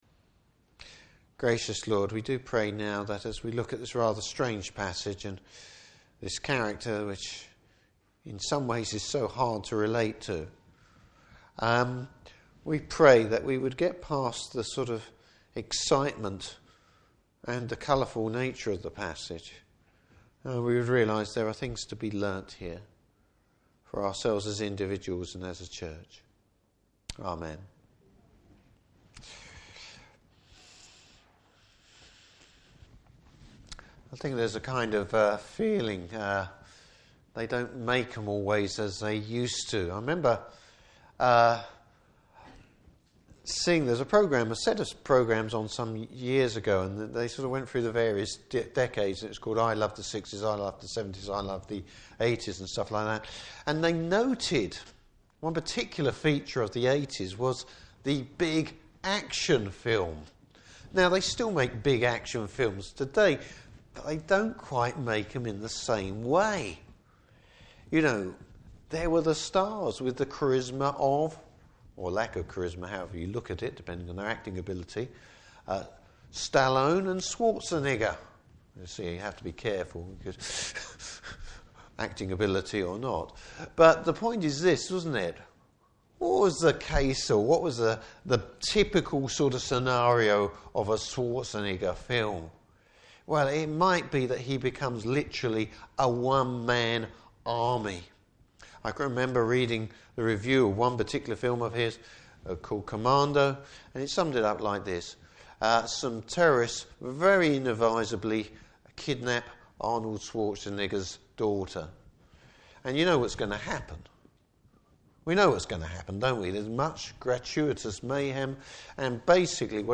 Service Type: Evening Service Bible Text: Judges 15.